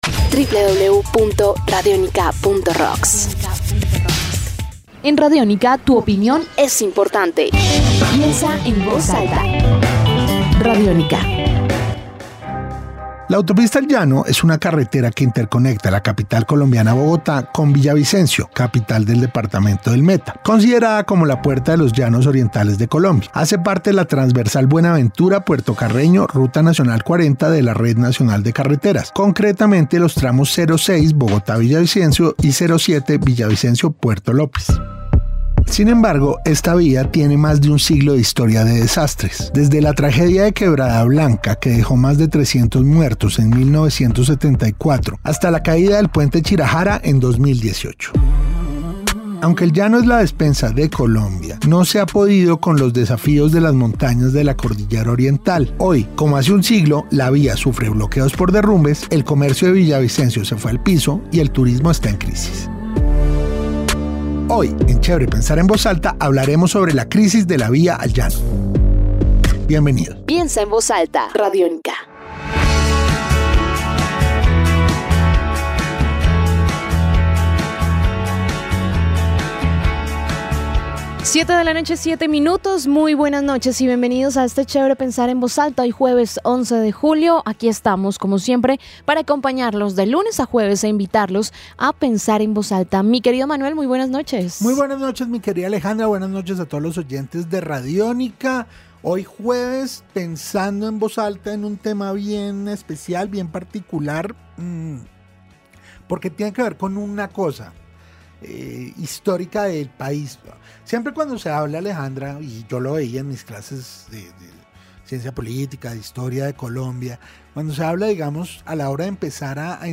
Tambien escuchamos en el programa a Manuel Felipe Gutiérrez, Viceministro de Infraestructura, quien desde Villavicencio nos explicó las distintas medidas que está tratando de tomar el gobierno para mitigar lo sucedido.